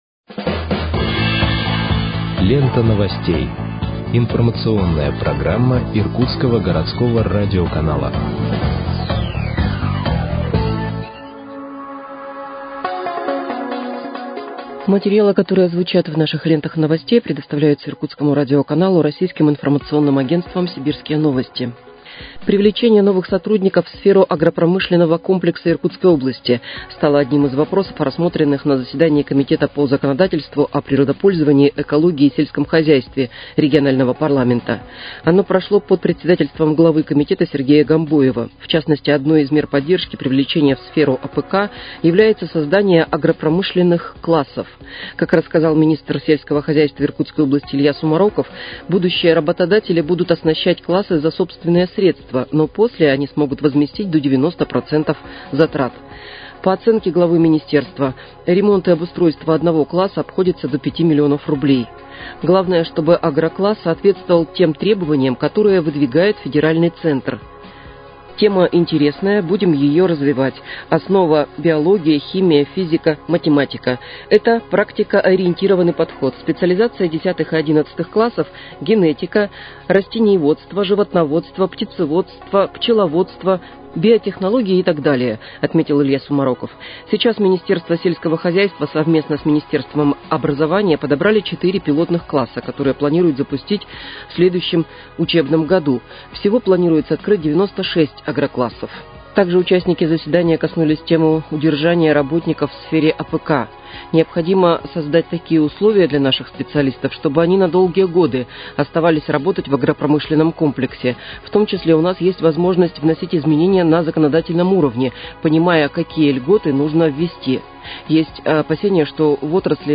Выпуск новостей в подкастах газеты «Иркутск» от 02.07.2025 № 1